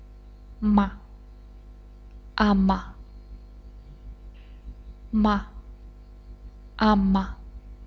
Consonnes - Sujet #1
m